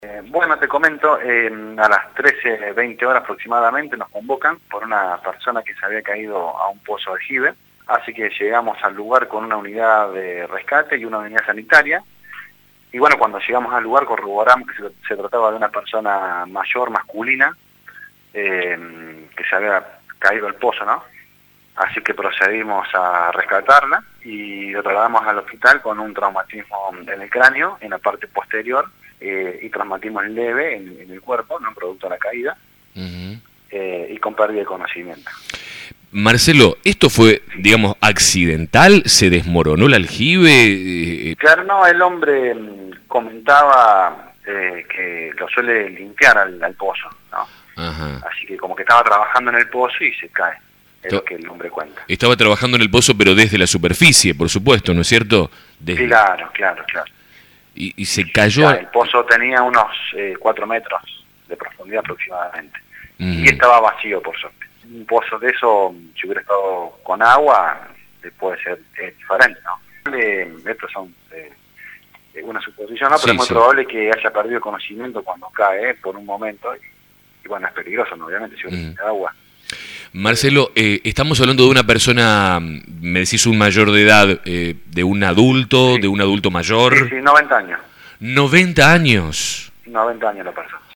dio detalles en conversaciones con La Mañana